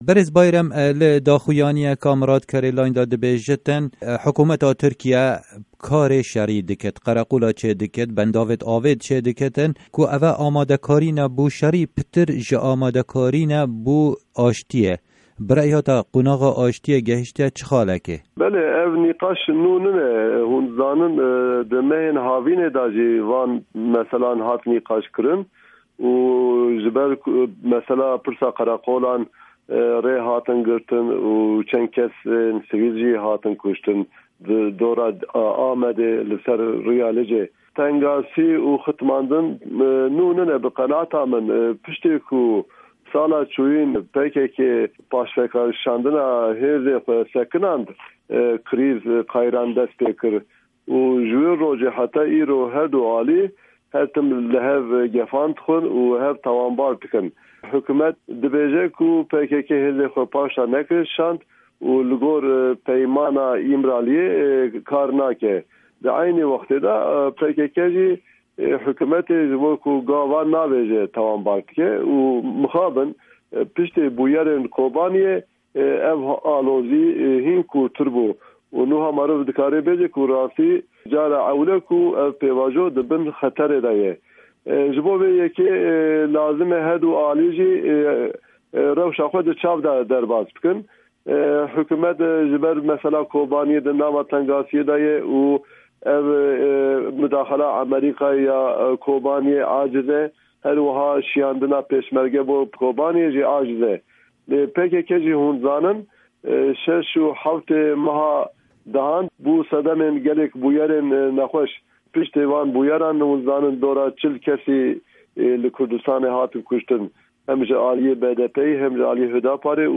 Hevpeyvêna